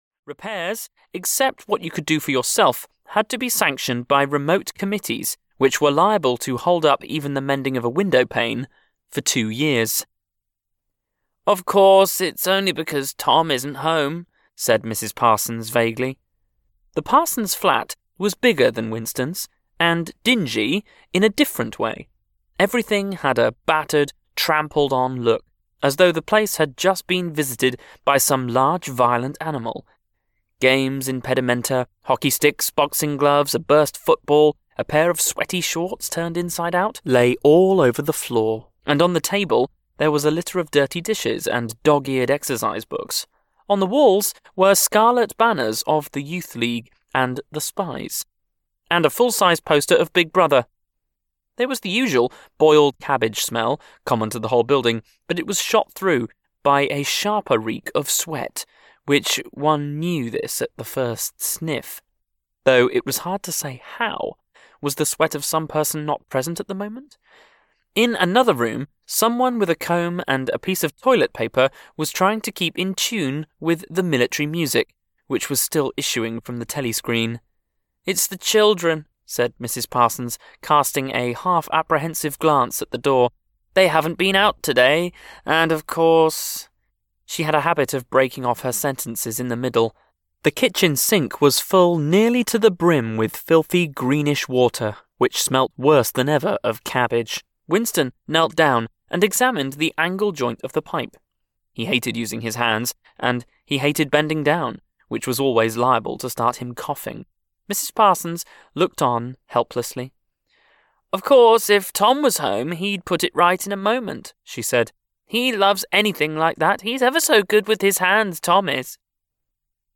1984 (EN) audiokniha
Ukázka z knihy